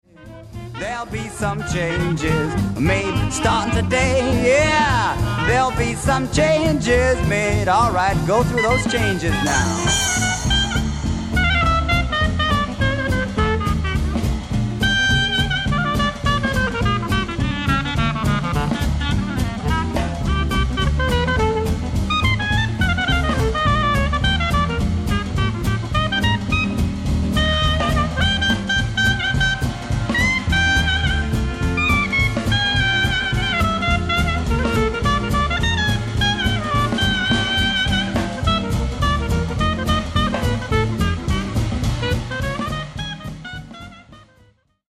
JUG MUSIC / AMERICAN ROOTS MUSIC / BLUES